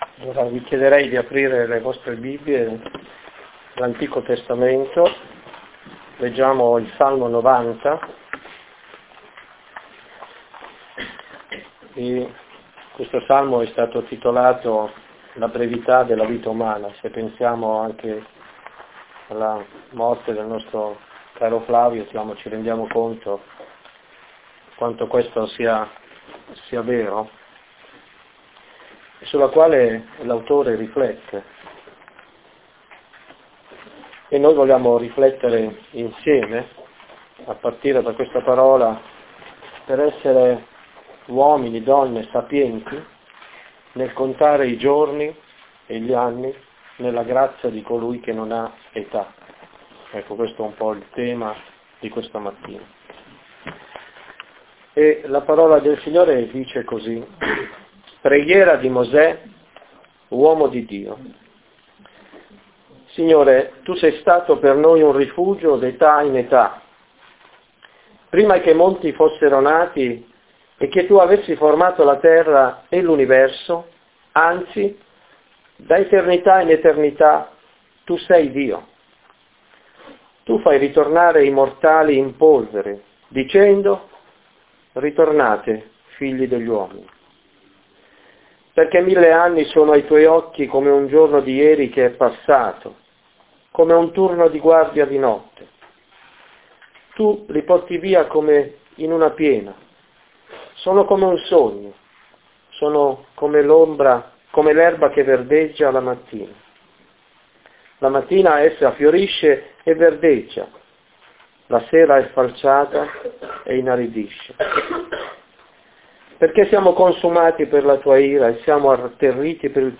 Predicazione di inizio anno « Chiesa Evangelica Riformata Battista, Balsamoxlacittà